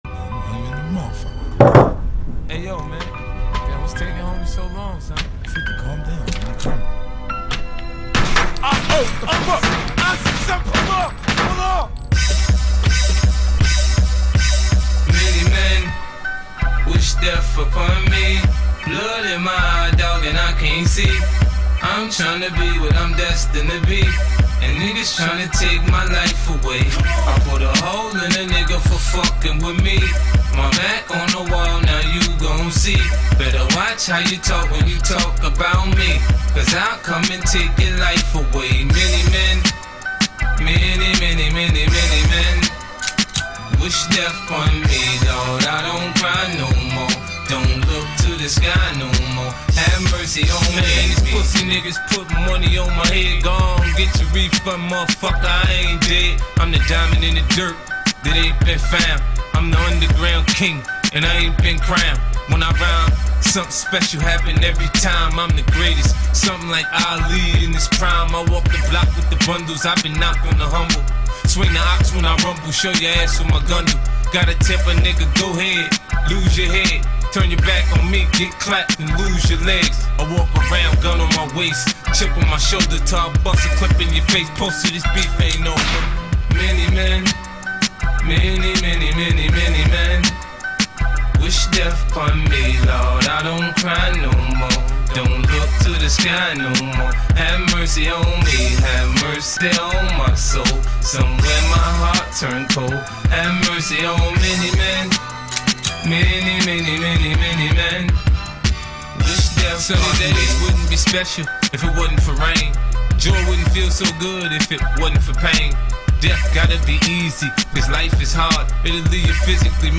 2025-01-31 20:57:12 Gênero: Rap; Hip-Hop; Gangsta Rap Views